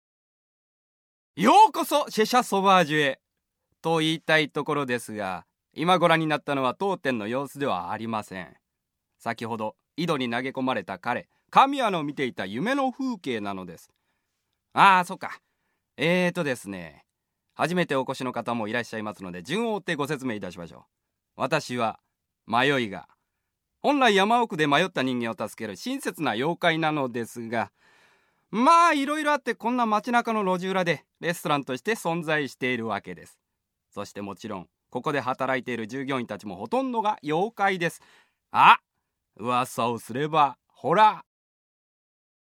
分割ボイスサンプル